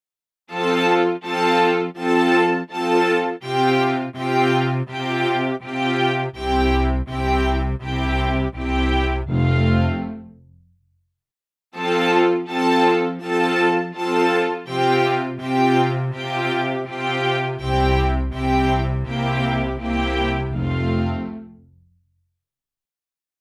Strings long detache with and without release till the end of the samples